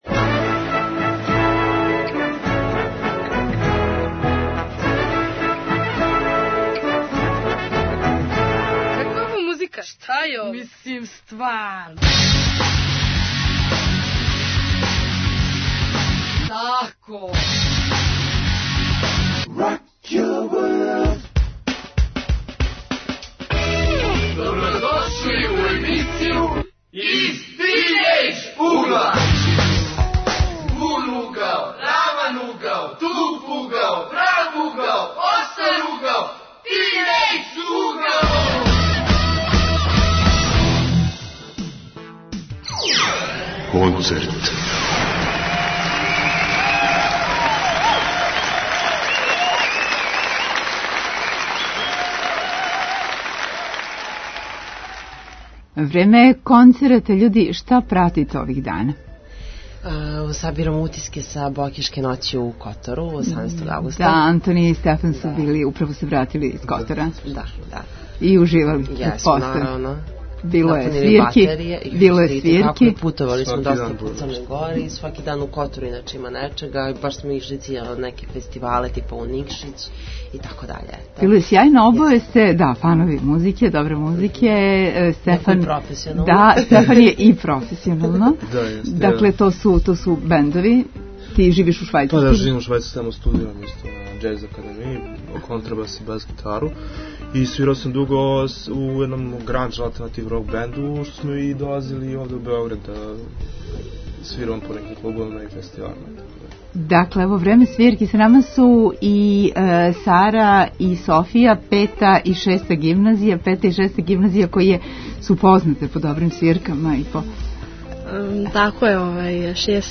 Питамо се ко због кога ради целе ноћи музичари ради тинејџерске публике или тинејџери ради омиљених бендова. Гости: средњошколци.